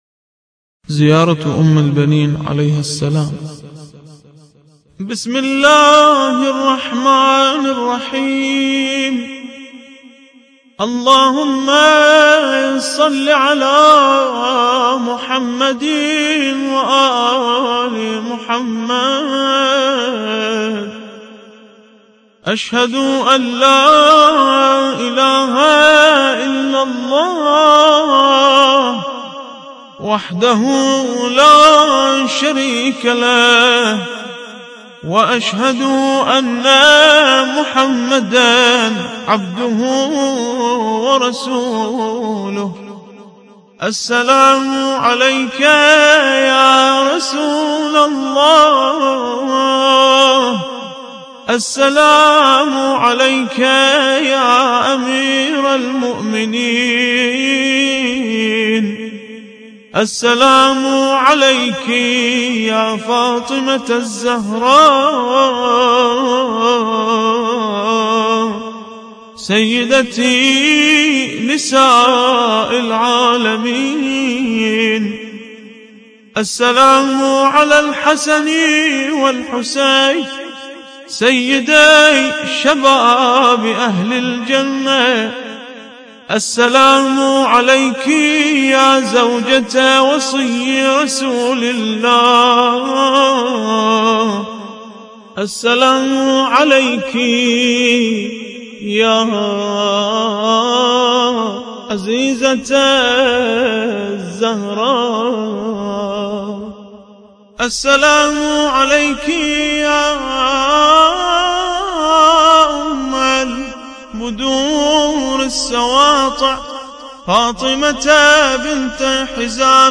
زيارة أم البنين عليها السلام – الرادود